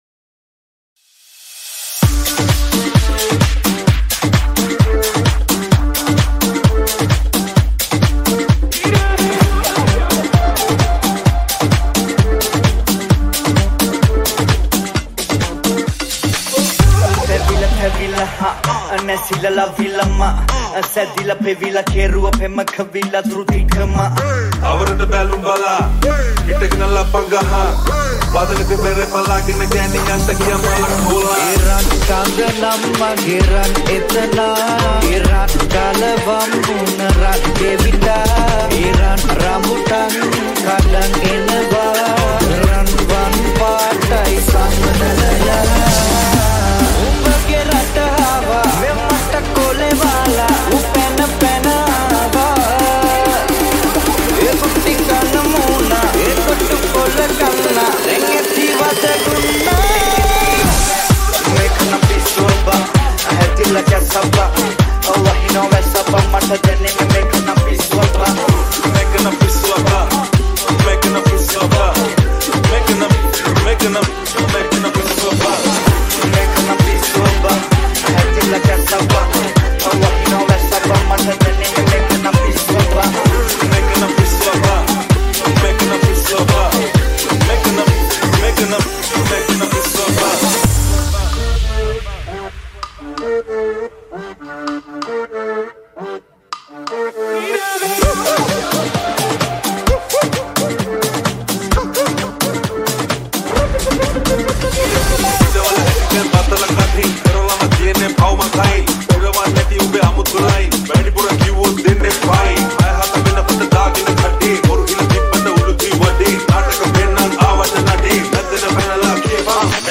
Party House Remix